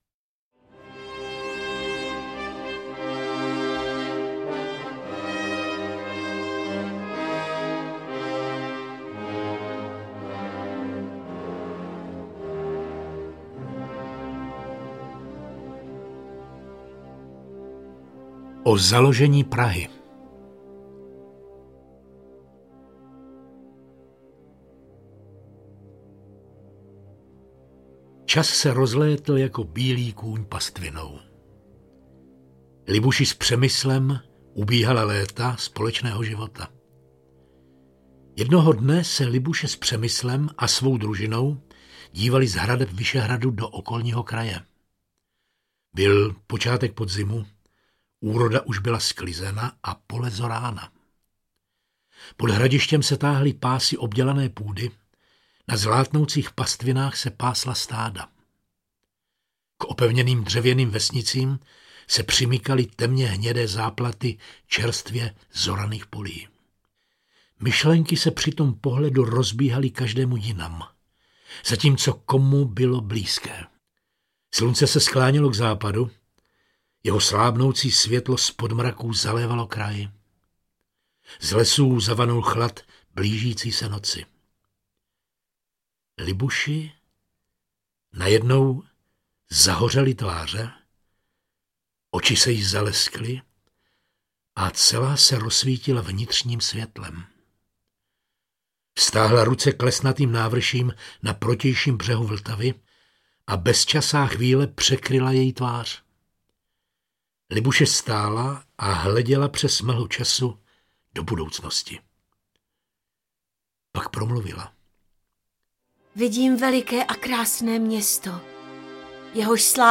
Staré pověsti české a moravské audiokniha
Ukázka z knihy
Symbiózu ženského a mužského principu pověstí z Čech představují interpreti Jan Kačer, Jan Potměšil a Veronika Gajerová, Boleslav Polívka čte příběhy z Velké Moravy.
• InterpretJan Potměšil, Jan Kačer, Bolek Polívka, Veronika Gajerová